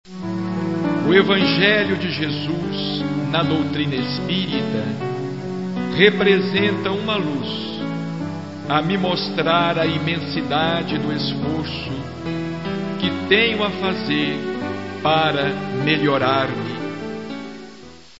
narra ensinamentos de Chico Xavier, o inesquecível médium, colhidos durante os vários anos de convivência. São relatos, recordações, vivências e lições preciosas que nos conduzem à reflexão e à paz interior.